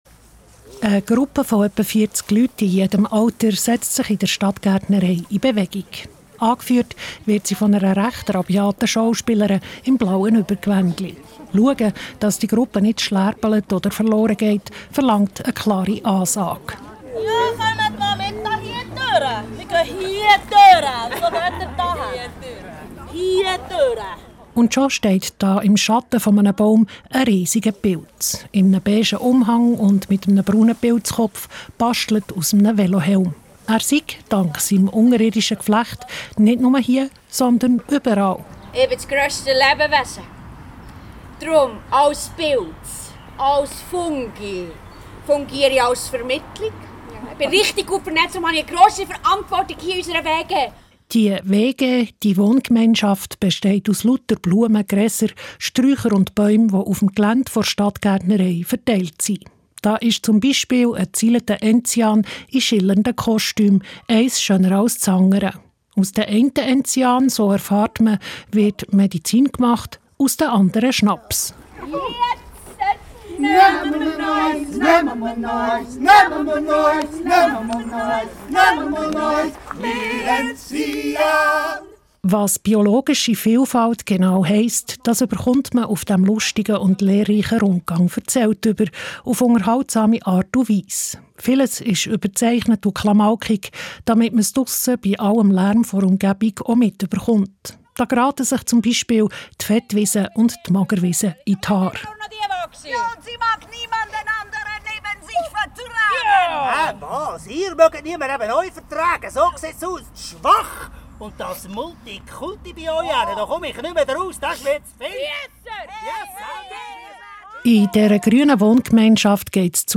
Rundgang-Stadtgaertnerei-DieWERsieTAeT.MP3